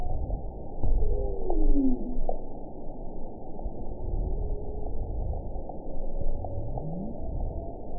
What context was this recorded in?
event 921881 date 12/20/24 time 08:29:56 GMT (4 months, 2 weeks ago) score 9.08 location TSS-AB03 detected by nrw target species NRW annotations +NRW Spectrogram: Frequency (kHz) vs. Time (s) audio not available .wav